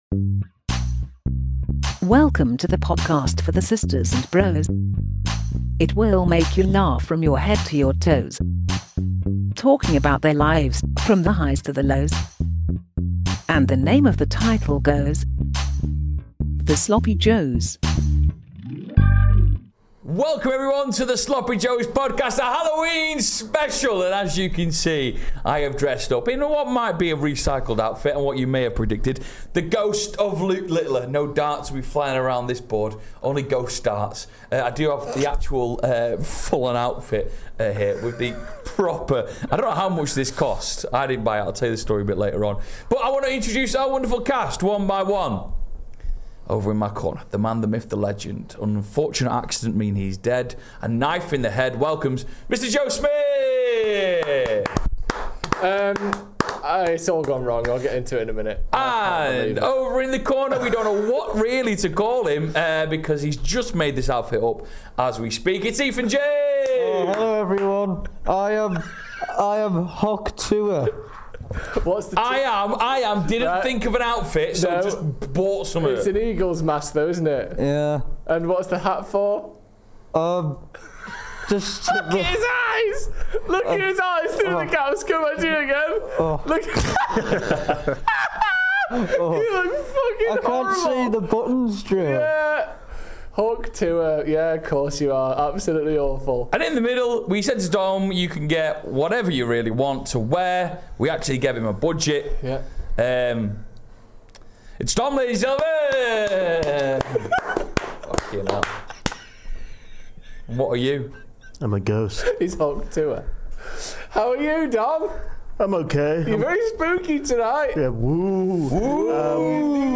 Halloween LIVE Special! | Ep.218 | Sloppy Joes Podcast